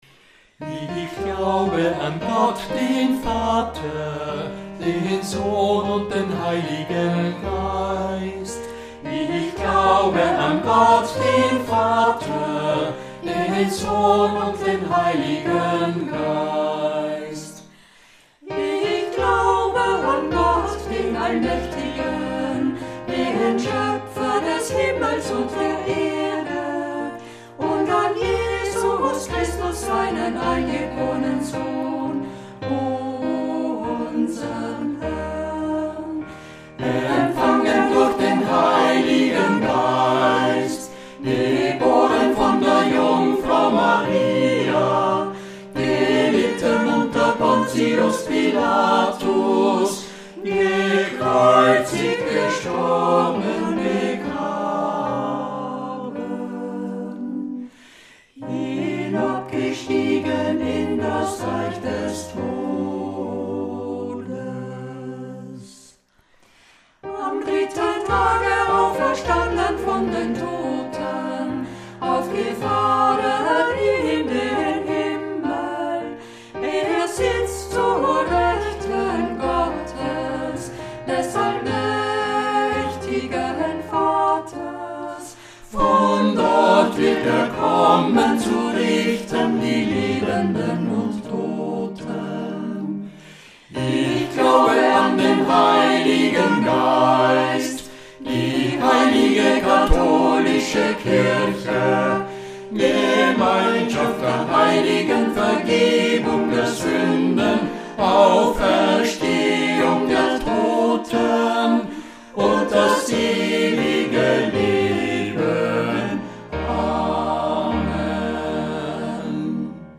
Messe in Es (2012) SATB mit Klavier/Orgel Ensemble-01-Herr erbarme dich 00:00 Ensemble-02-Ehre sei Gott 00:00 Ensemble-03-Ich glaube 00:00 Ensemble-04-Du bist heilig 00:00 Ensemble-05-Lamm Gottes 00:00
Ensemble-03-Ich-glaube.mp3